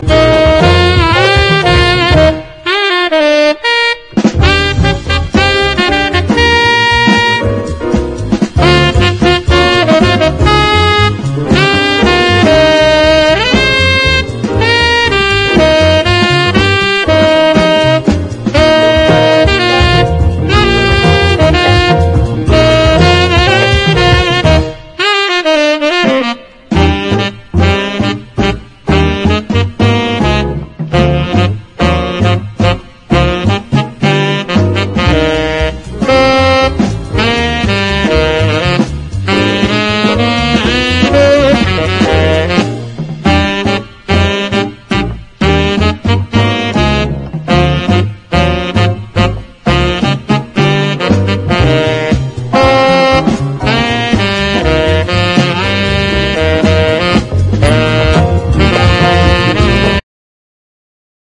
WORLD / LATIN / CUMBIA
反復パーカッションで攻め立てる
民謡調メロが沁みる
ダンサブルで高揚感抜群の